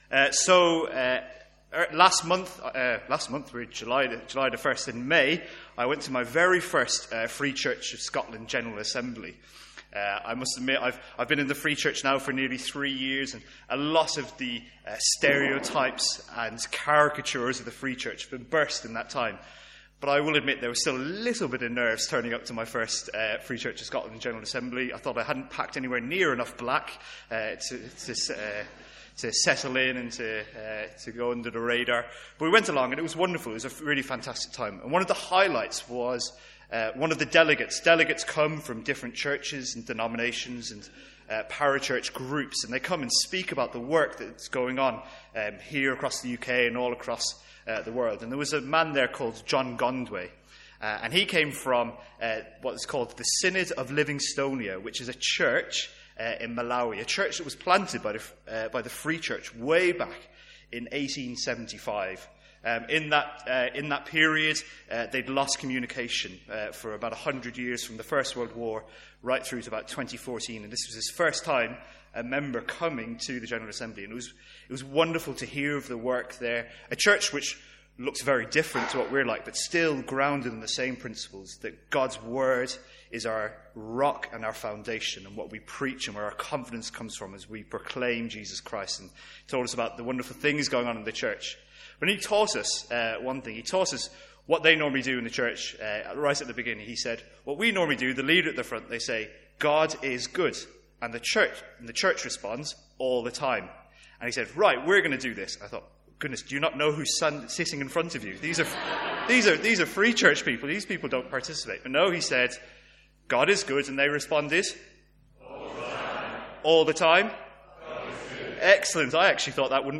Sermons | St Andrews Free Church
From our morning series in the Songs of Praise.